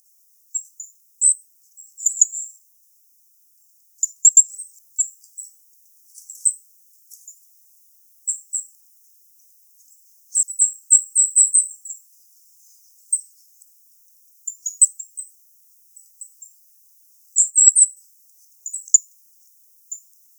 Regulus regulus - Goldcrest - Regolo
- IDENTIFICATION AND BEHAVIOUR: In a small mixed wood on the shore of a lagoon eight Goldcrests are perched on a big Cork oak (well in sight).
E 11,2119° - ALTITUDE: 0 m. - VOCALIZATION TYPE: calls.
Note that there are at least three different call types in this audio sample.